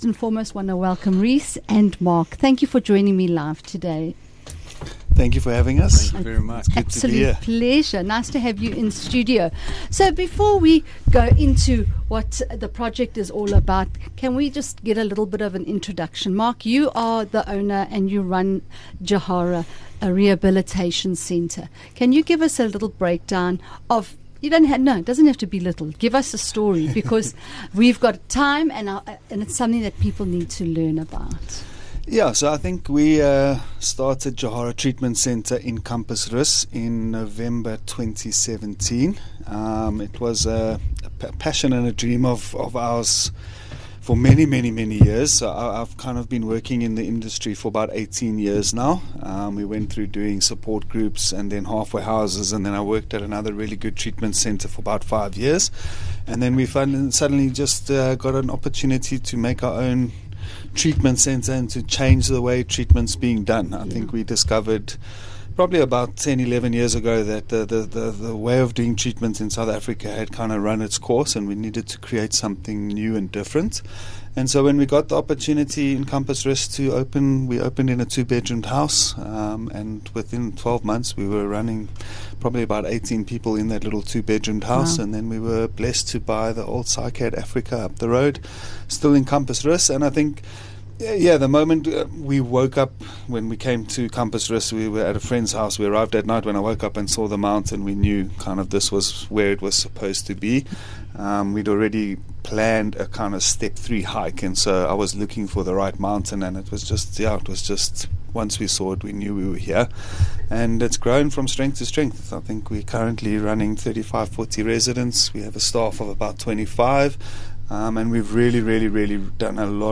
13 Sep Interview